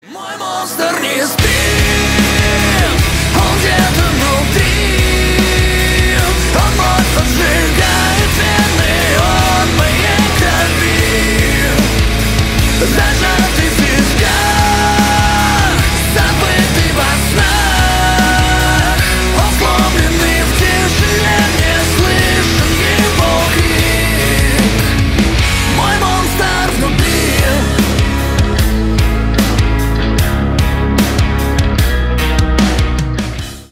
громкие
металл